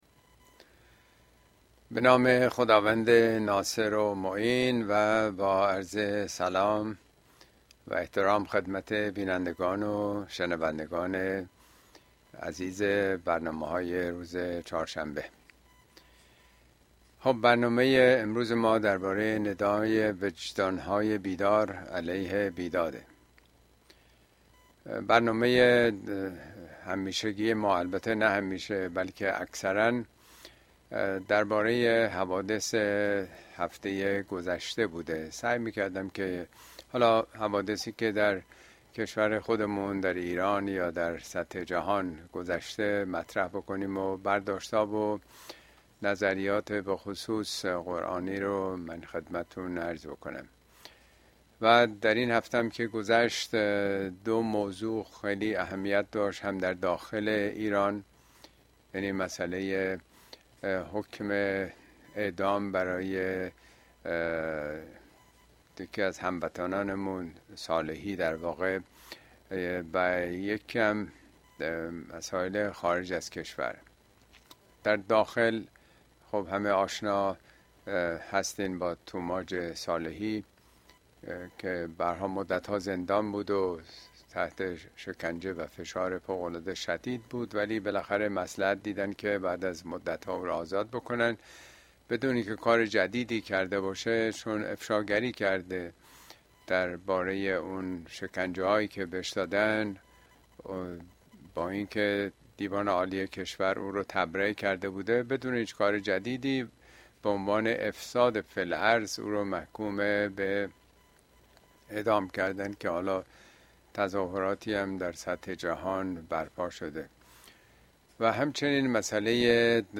Speech
` موضوعات اجتماعى اسلامى !ندای وجدان‌های بیدار در برابر بیداد اين سخنرانى به تاريخ ۱ می ۲۰۲۴ در كلاس آنلاين پخش شده است توصيه ميشود براىاستماع سخنرانى از گزينه STREAM استفاده كنيد.